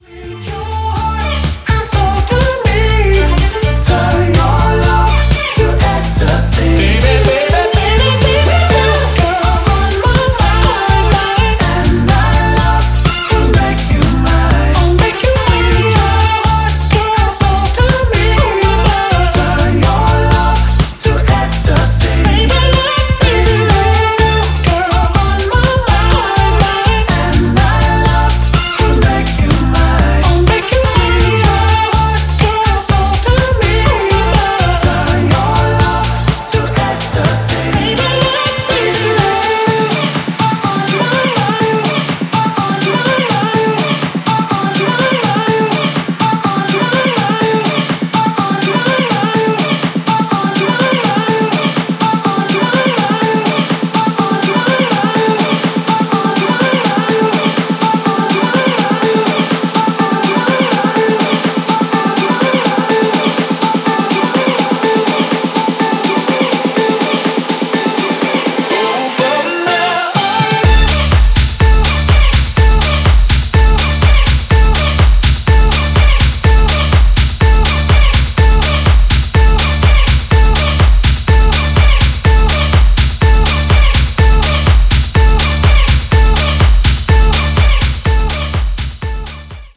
EXTENDED MIX
ジャンル(スタイル) DEEP HOUSE / DISCO HOUSE